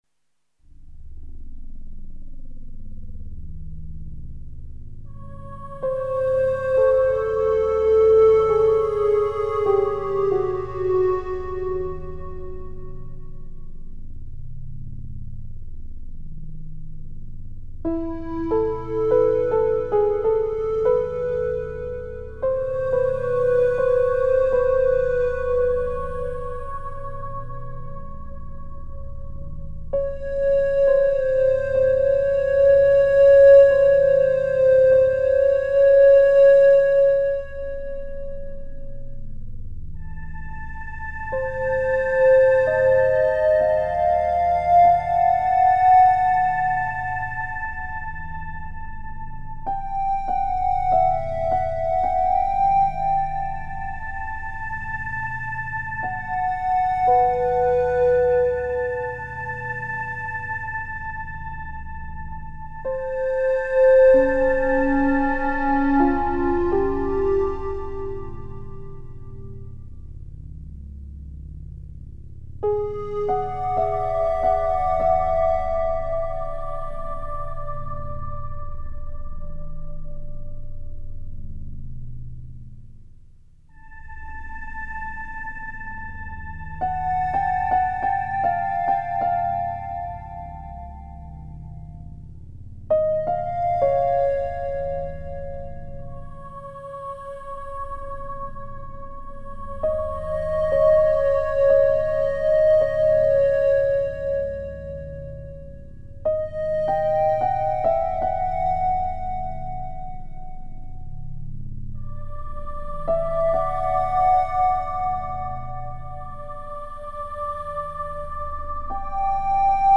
The installation comprises a automatic weather station connected, via a computer, to an electronic musical instrument.
Thus, when the wind blows, phrases are generated whose pitch, intensity and statistical density reflect wind speed and direction; other notes change with the rise and fall of temperature or pressure; and random percussive events occur with changes in rainfall.